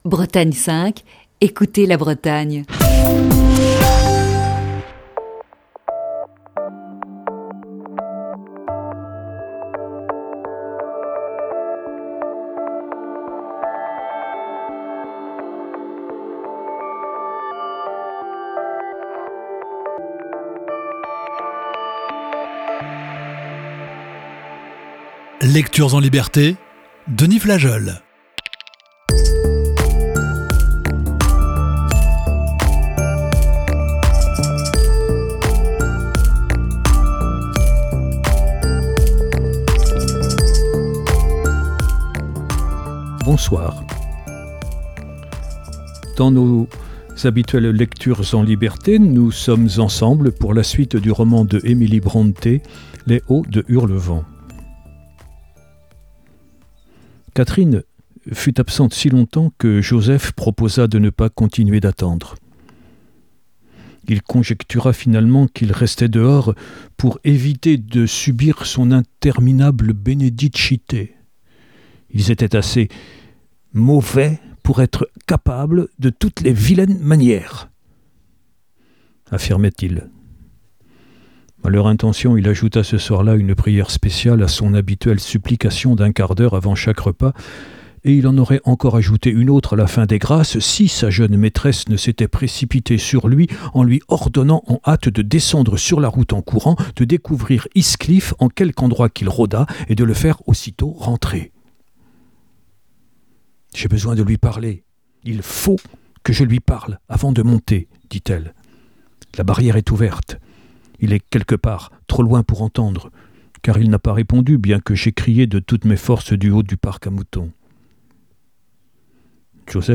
Émission du 27 mai 2021.